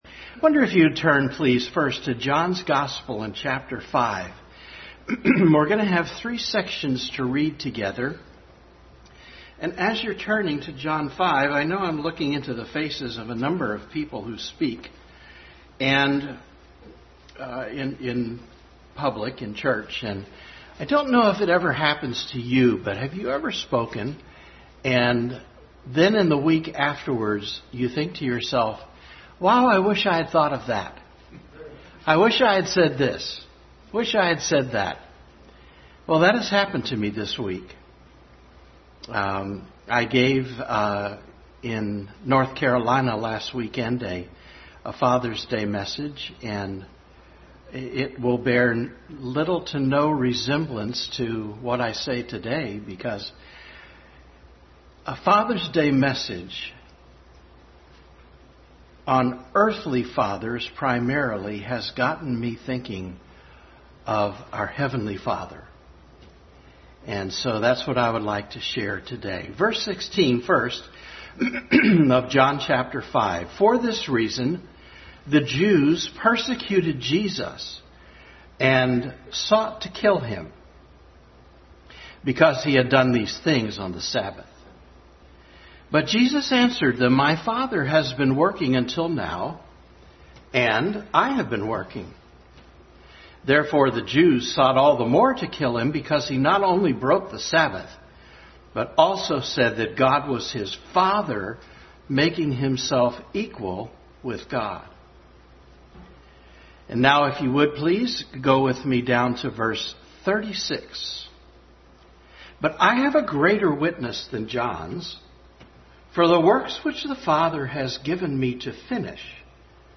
Family Bible Hour message.
14-21 Service Type: Family Bible Hour Family Bible Hour message.